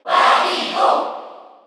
Category: Crowd cheers (SSBU) You cannot overwrite this file.
Wario_Cheer_Korean_SSBU.ogg.mp3